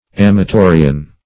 amatorian - definition of amatorian - synonyms, pronunciation, spelling from Free Dictionary Search Result for " amatorian" : The Collaborative International Dictionary of English v.0.48: Amatorian \Am`a*to"ri*an\, a. Amatory.